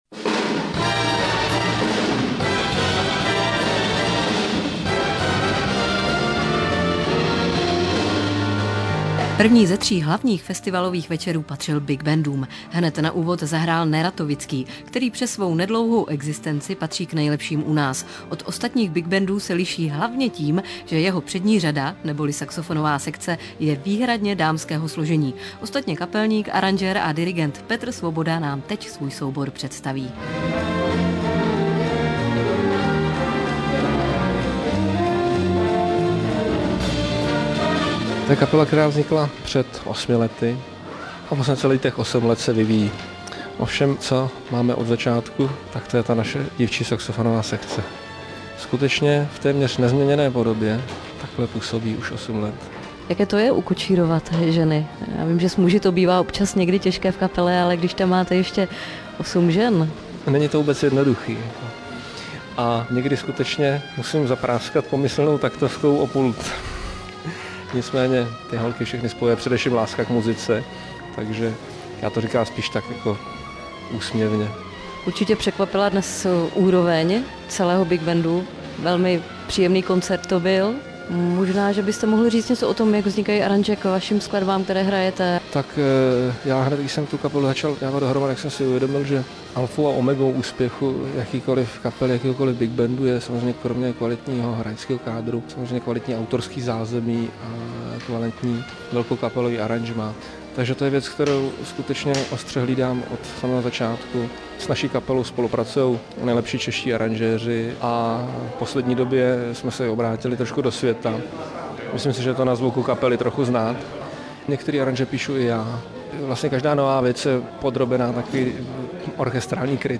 Rozhlasová reportáž - Český rozhlas Hradec Králové       Rozhlasová reportáž - Český rozhlas Hradec Králové